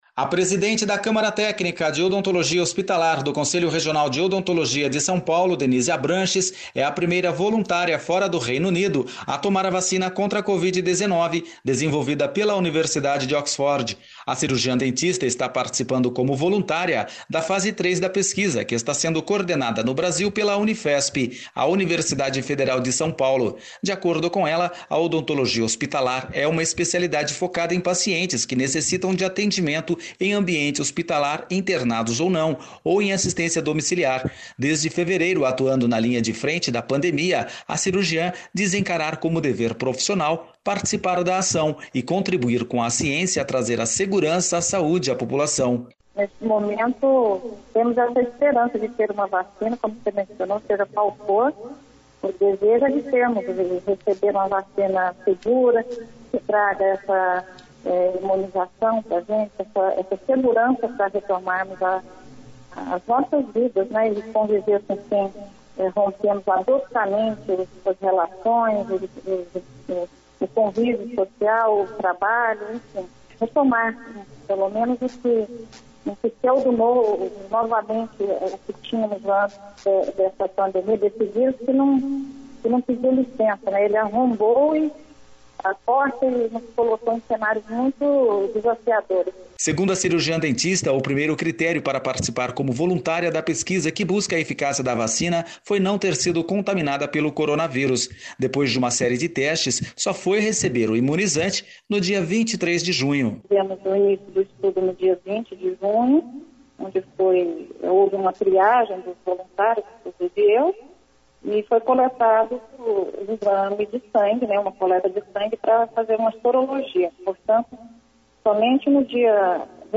Voluntária em teste de vacina conta experiência